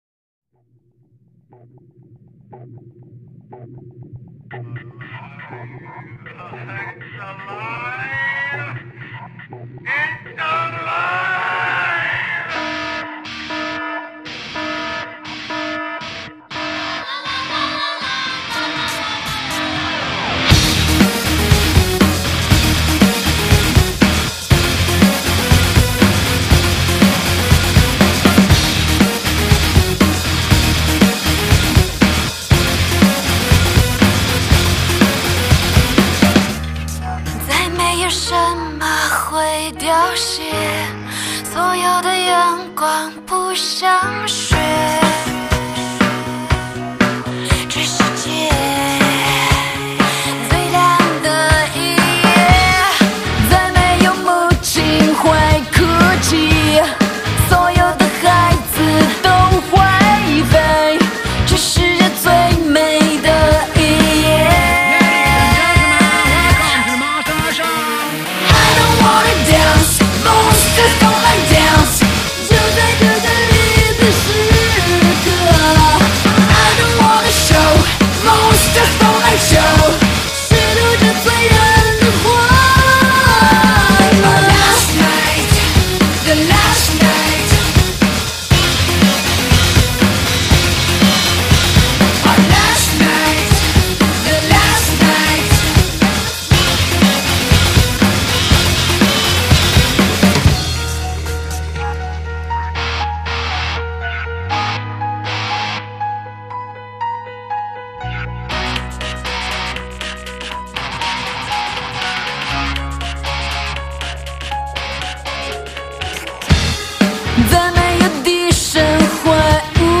机械的鼓点，饱满的贝司，工整切割的吉他riff，大量充满节日气氛的采样音色，从甜蜜到坚定的女声